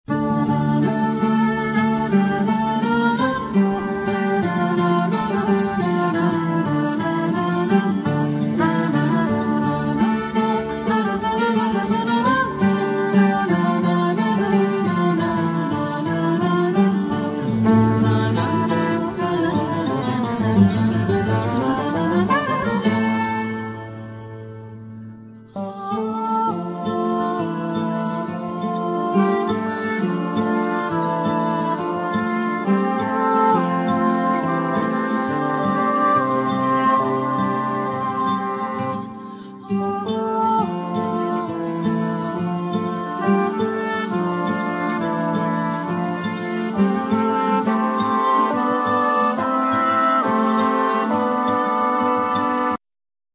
16 string acoustic guitar,El.guitar,Vocals
5 string electric bass
Soprano Saxophone
Piano
1st Violin